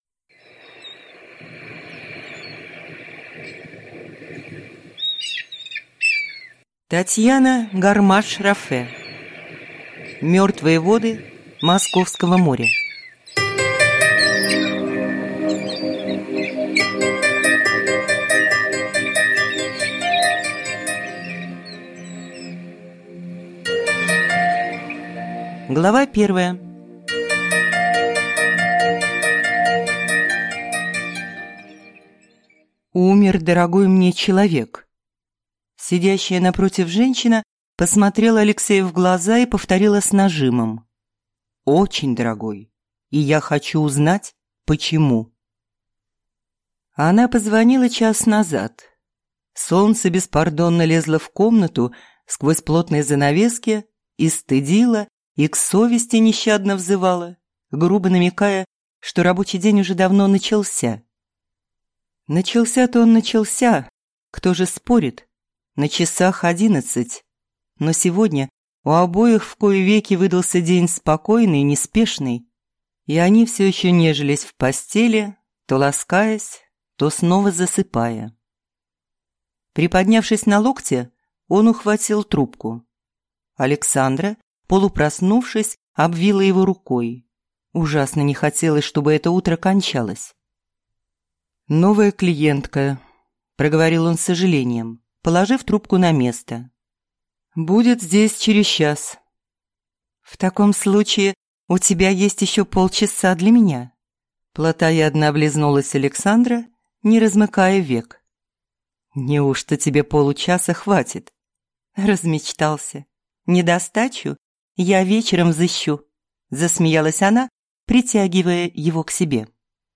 Аудиокнига Мертвые воды Московского моря | Библиотека аудиокниг